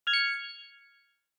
disinfect_1.mp3